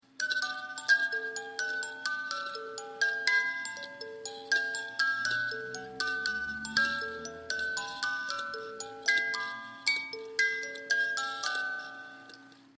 musique : Valse de l'Empereur - ré majeur - durée 2 min 40 s -
En effet, la bouteille musicale de l'angelino est constituée d'une boîte à musique qui se remonte à la main, aussi pour un cycle complet de remontée mécanique la danseuse peut interpréter en moyenne jusqu'à 20 fois le mot choisi.